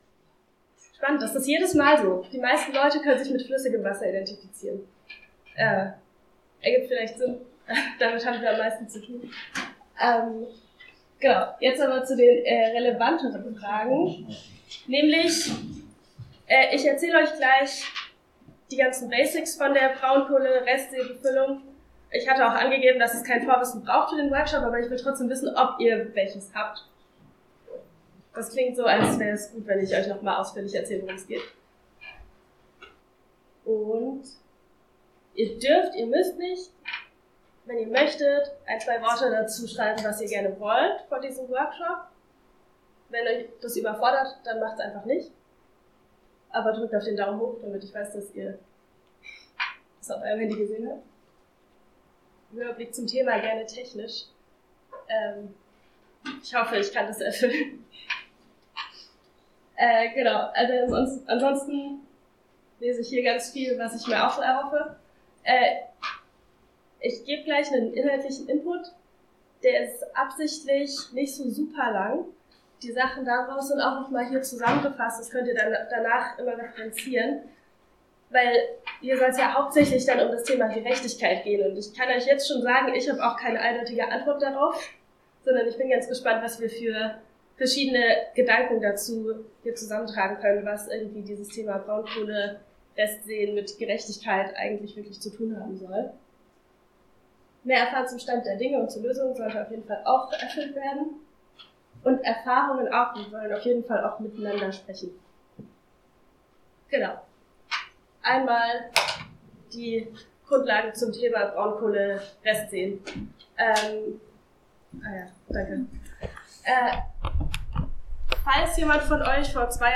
Wasserkonferenz: Workshop – Braunkohlerestseen – wie kann das noch gerecht sein?